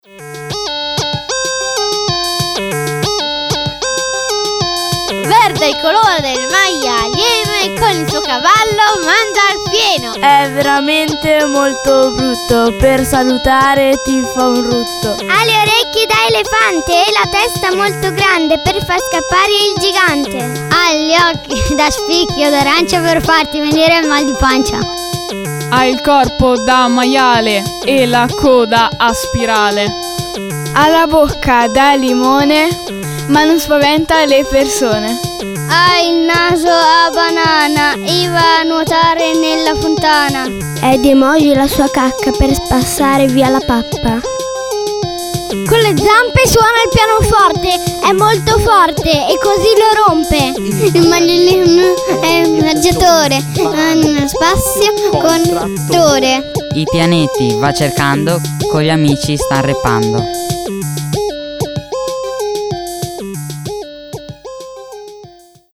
LA VOSTRA CANZONE RAP!!